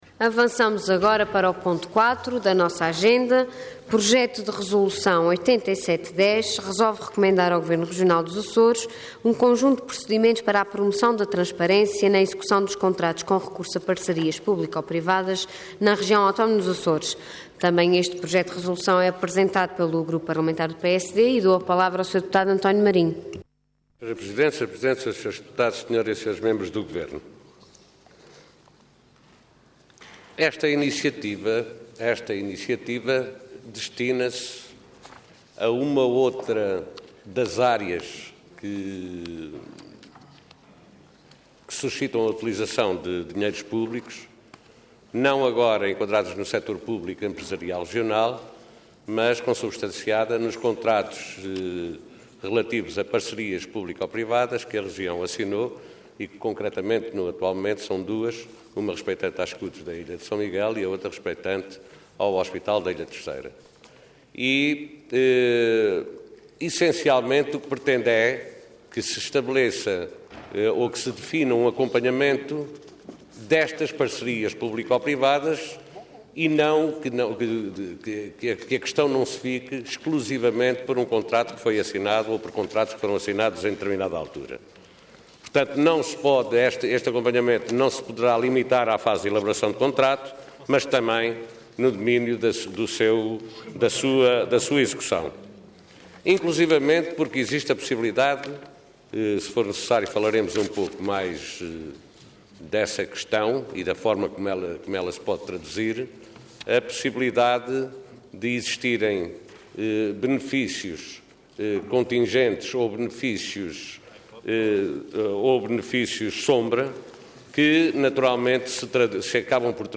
Assembleia Legislativa da Região Autónoma dos Açores
Intervenção
António Marinho
Deputado